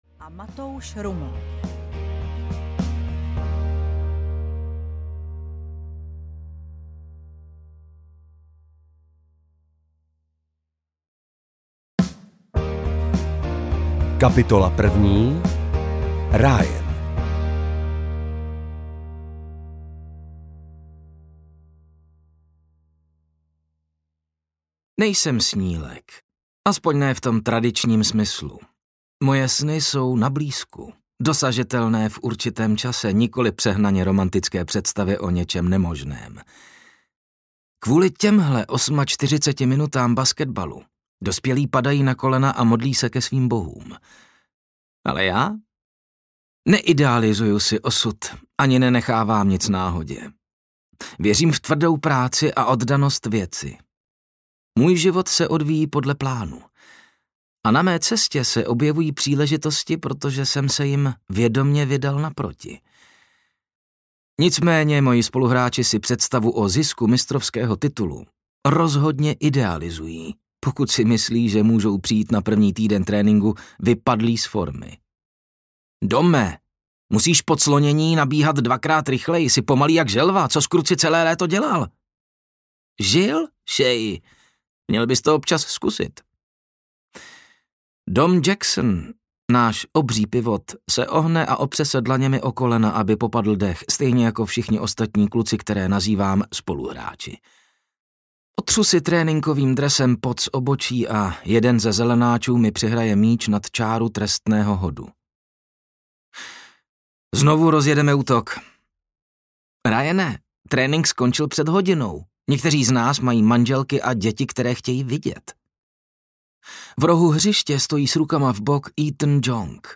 Správný krok audiokniha
Ukázka z knihy